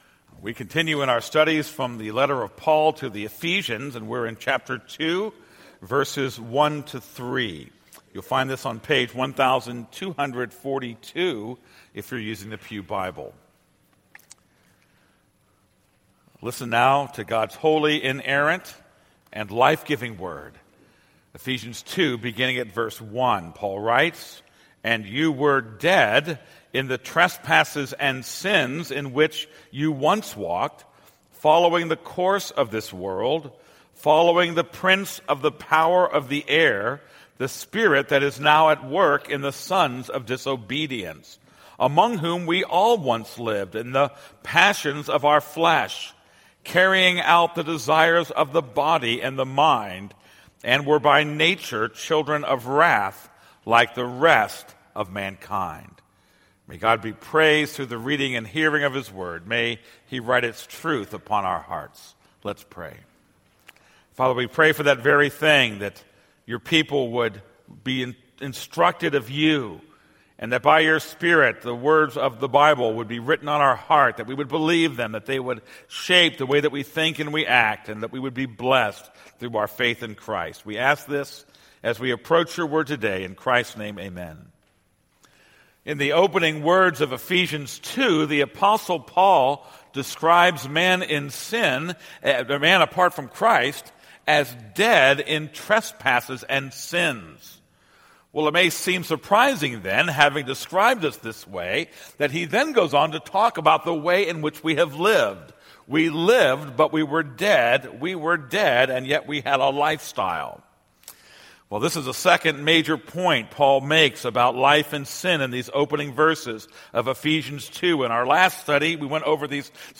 This is a sermon on Ephesians 2:1-3.